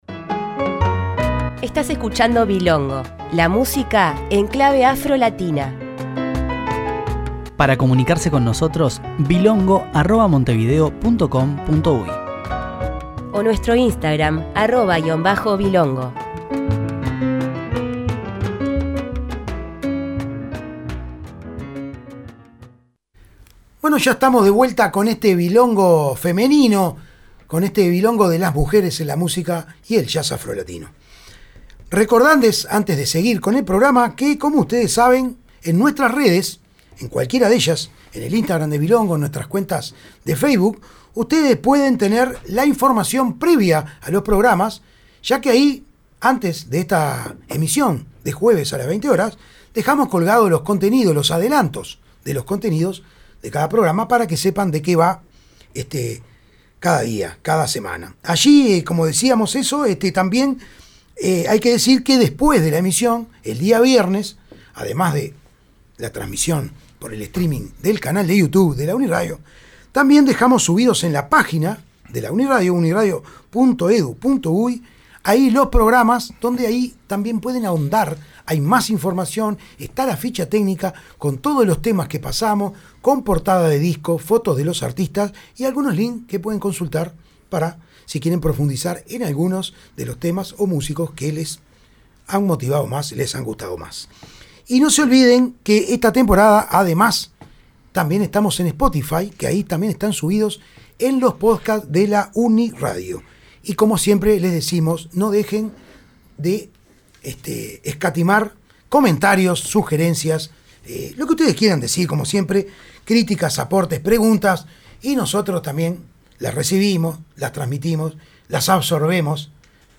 Hoy en Bilongo: Mujeres en el Jazz y la Música Afrolatina